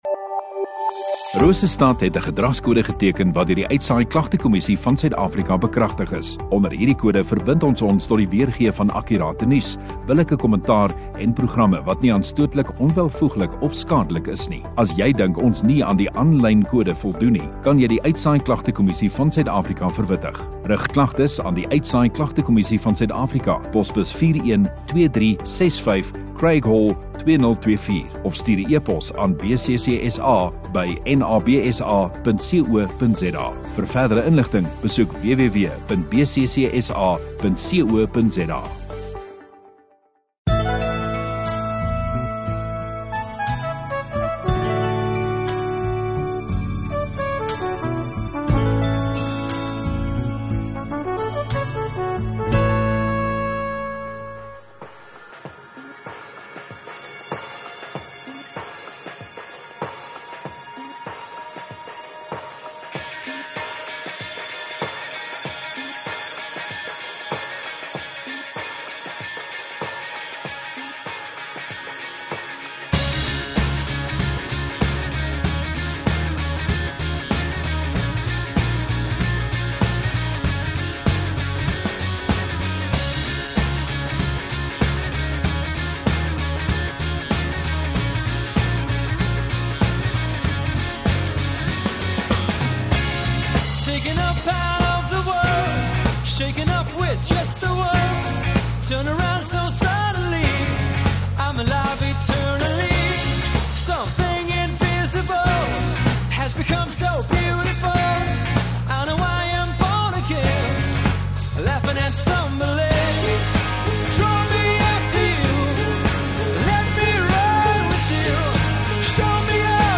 7 Apr Sondagaand Erediens